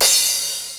T4_Crash1.wav